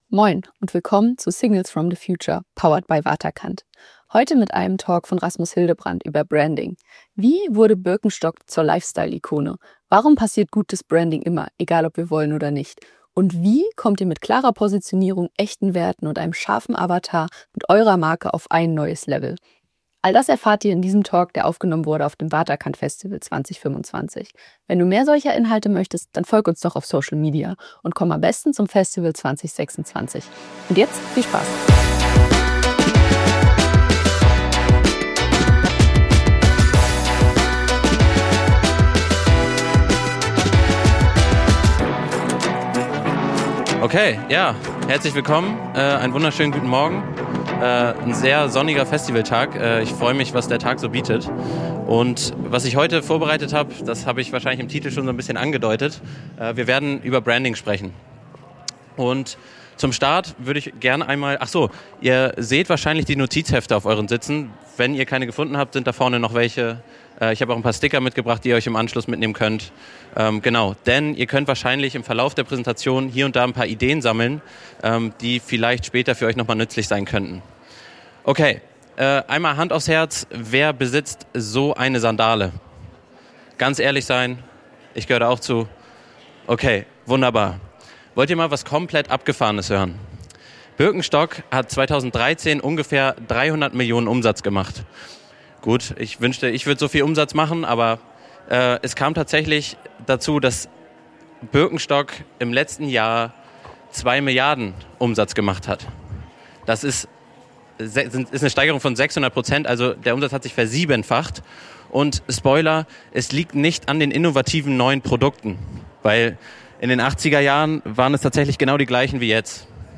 Am Beispiel von Birkenstock zeigt er, wie sich Unternehmen durch kluge Positionierung, klare Werte und echtes Verständnis für ihre Zielgruppe radikal neu erfinden können – ganz ohne ihr Produkt zu verändern. Warum Branding keine Kür, sondern Überlebensstrategie ist Warum gute Brands bewusst entstehen – und schlechte zufällig Und warum du nicht dein Produkt verkaufst, sondern die Welt drumherum Ein Talk für alle, die Marken bauen, Marken führen – oder sich von einer mitreißen lassen wollen.